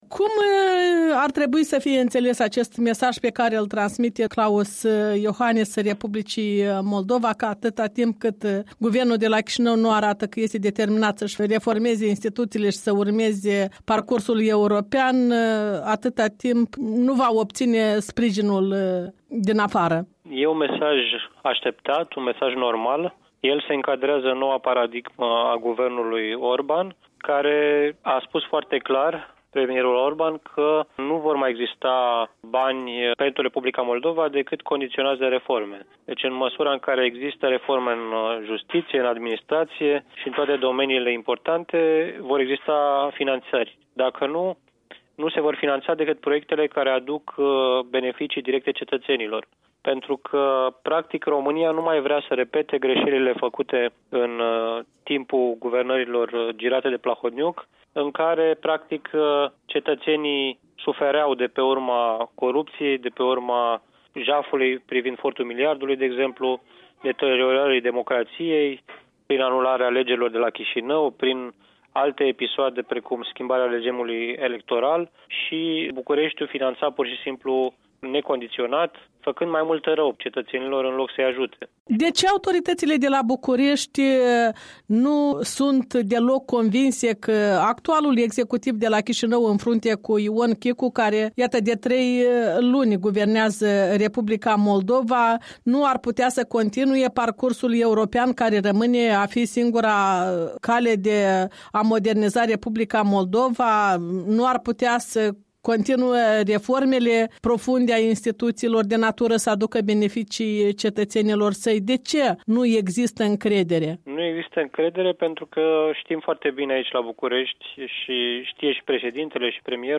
Interviu cu Matei Dobrovie